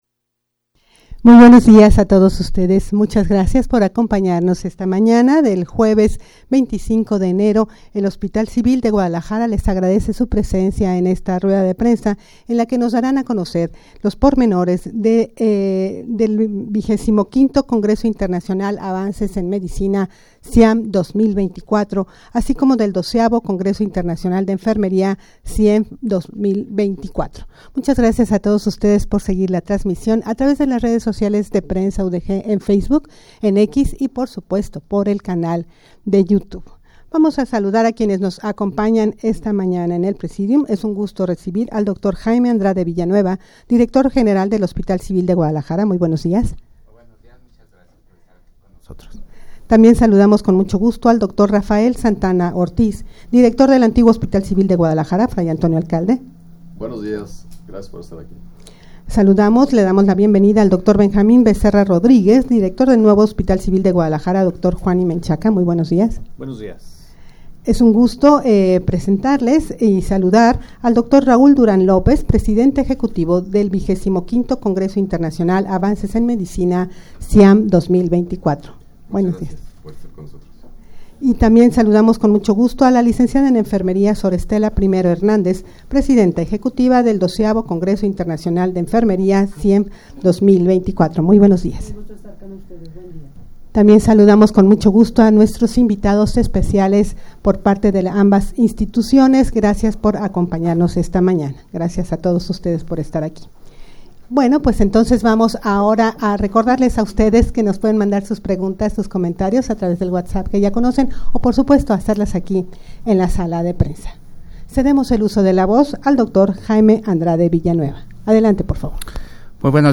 rueda-de-prensa-para-dar-a-conocer-la-realizacion-del-xxv-congreso-internacional-avances-en-medicina.mp3